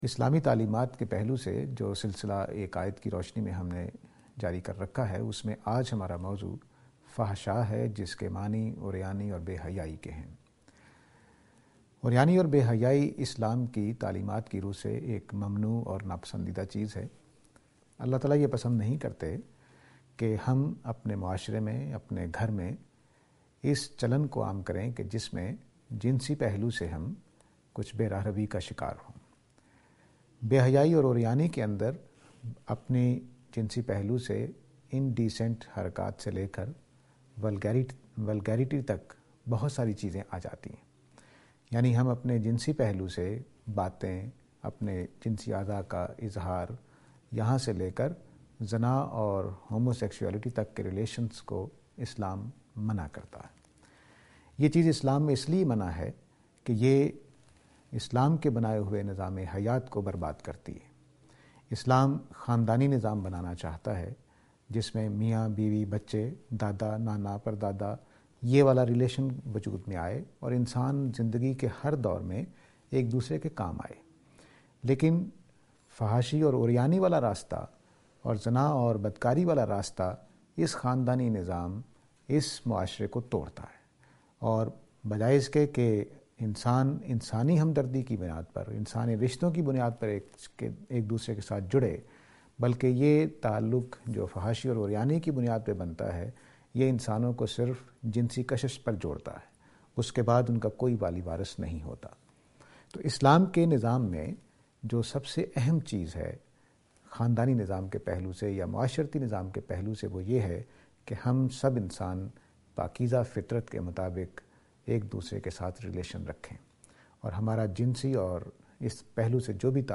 This lecture is and attempt to answer the question "Nudity and Vulgarity".